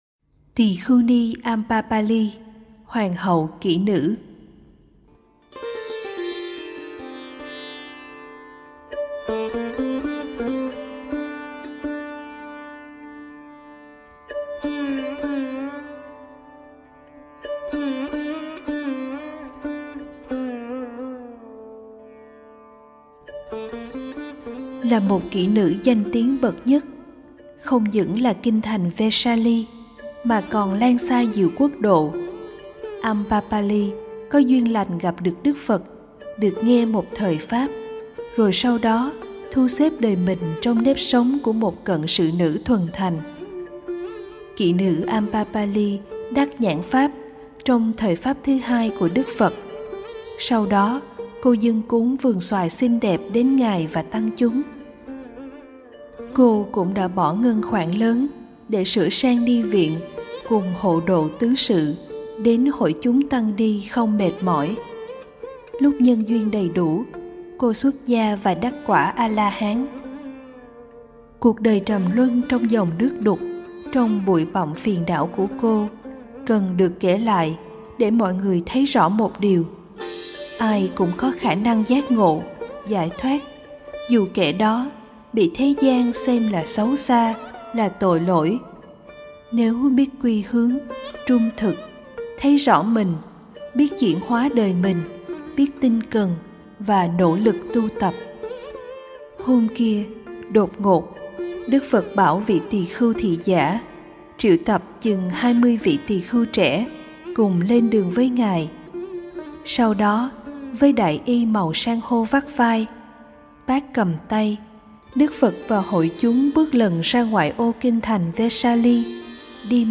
11 Tỳ Khưu Ni Ambapali Hoàng Hậu Kỹ Nữ - Con Gái Đức Phật-Sách Nói Phật Giáo.mp3